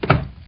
PixelPerfectionCE/assets/minecraft/sounds/mob/horse/wood1.ogg at mc116